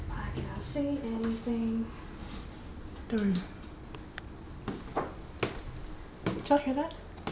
EVP 6 – We aren’t sure on what this one says, if you can make it out let us know.
EVP4_unknown-but-clear.wav